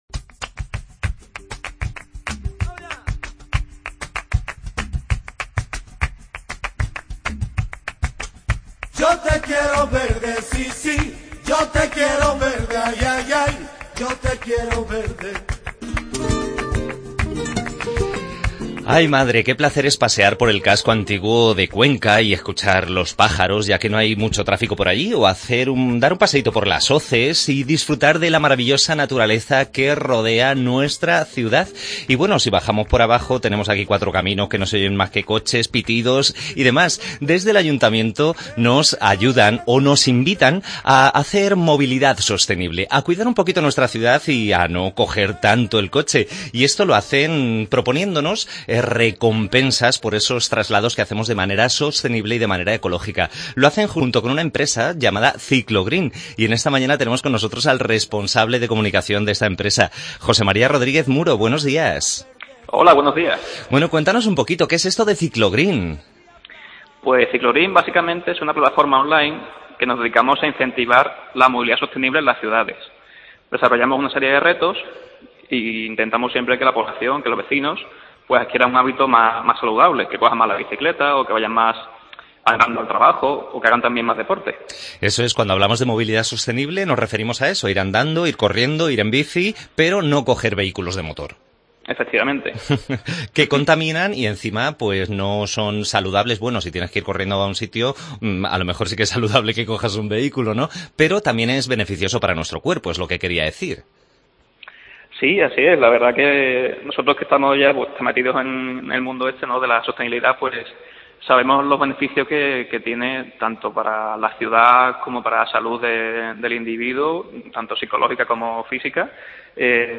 AUDIO: Entrevista a la empresa Ciclogreen que junto con el Ayuntamiento de Cuenca propone el programa de recompensas "Muevete por Cuenca".